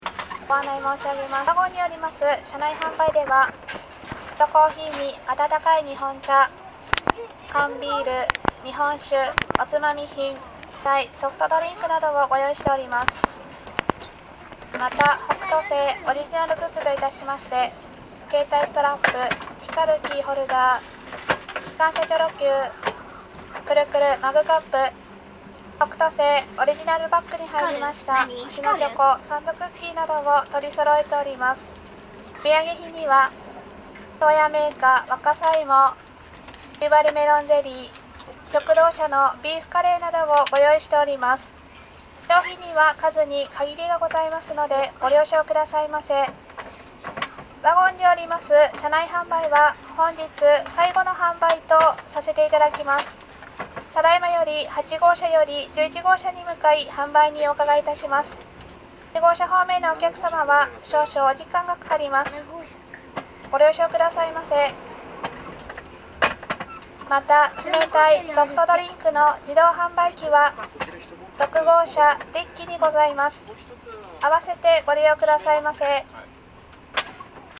と、同時に車内販売のご案内は